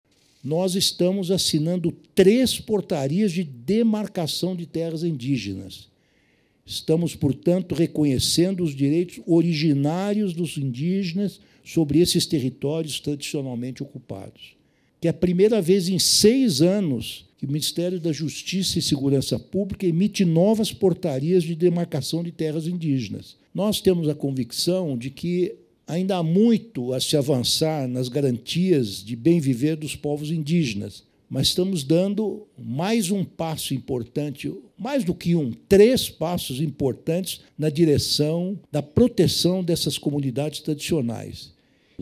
Ministro Lewandowski fala sobre a pacificação do país durante o desfile de 7 de setembro.mp3 — Ministério da Justiça e Segurança Pública